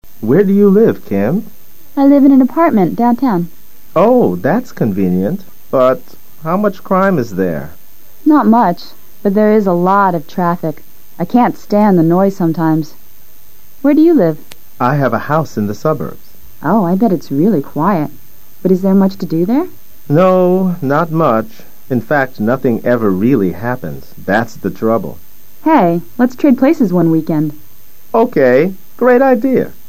Listening Test 2/16
LISTENING TEST 2/16: Ahora, basándote en los comentarios del diálogo anterior, selecciona la opción más adecuada para cada pregunta.